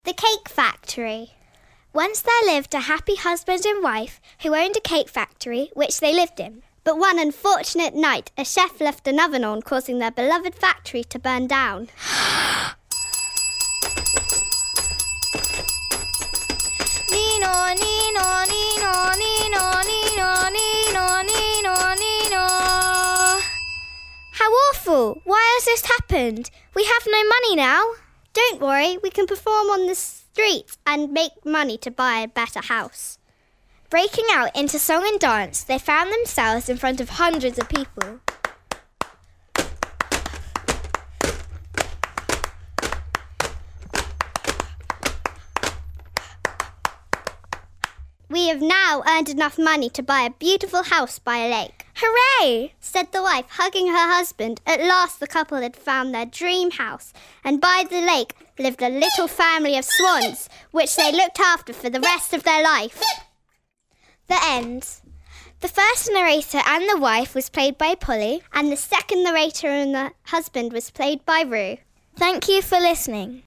The WordPlay studio travelled to Village Books where six teams took up the challenge to script & broadcast their very own radio plays.